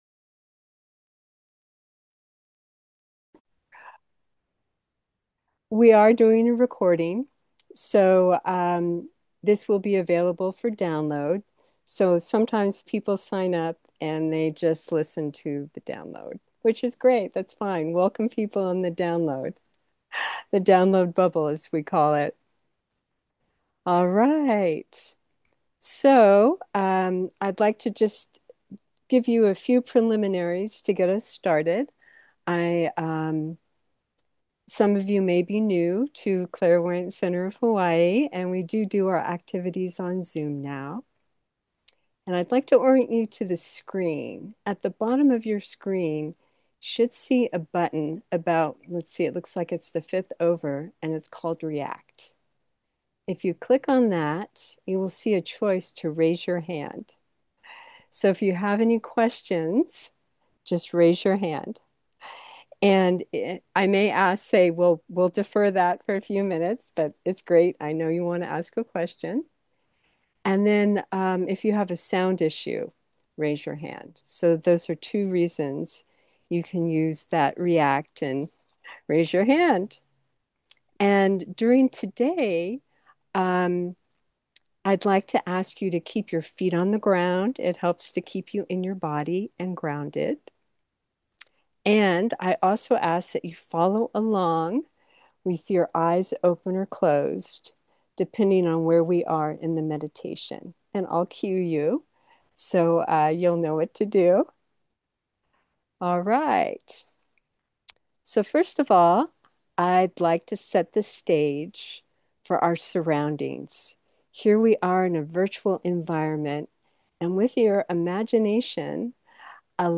32 minutes Recorded Live on January 15